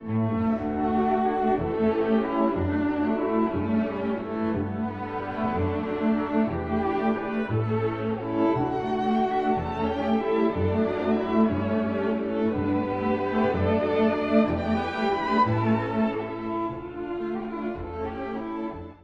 (第一主題) 古い音源なので聴きづらいかもしれません！
第二主題は、とても旋律的。
味わい深い第一主題と、より流麗な第二主題の対比が印象的です。